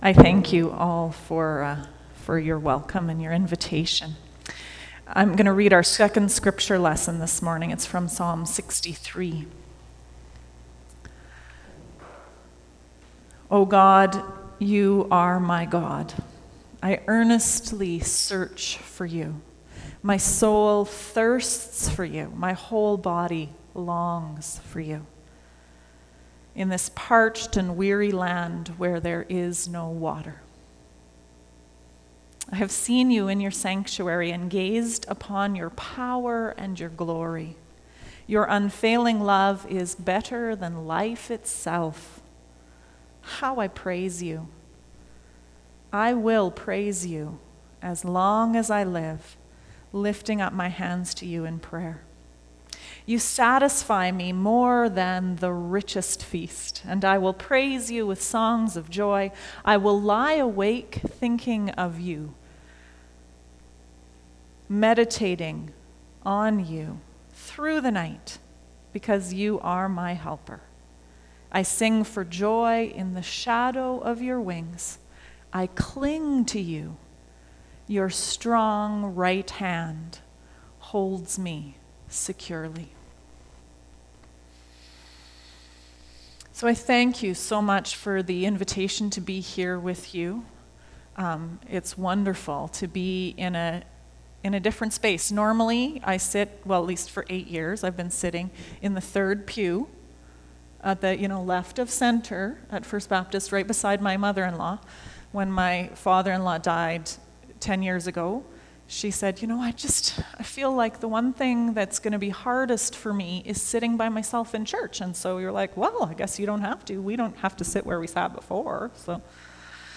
Argyle Road Baptist Church
Sermons